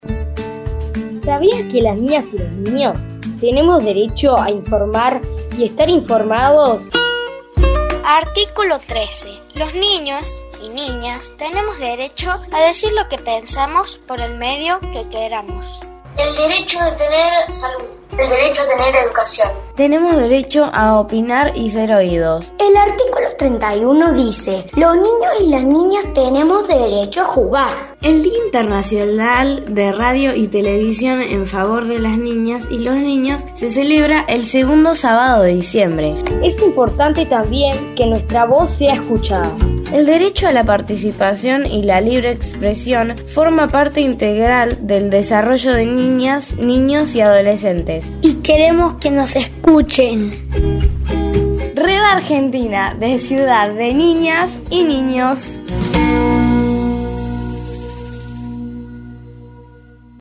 SPOT PARA RADIOS GRABADO POR NIÑAS Y NIÑOS DE TODA LA ARGENTINA
La Red Argentina “La Ciudad de las Niñas y los Niños” ofrece a las emisoras de radio de todo el país, un spot grabado por niñas y niños de distintas ciudades de todas las provincias que conforman esta red, para su emisión pública en el marco del Día Internacional de la Radio y la Televisión a favor de las Infancias.